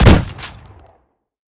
pump-shot-5.ogg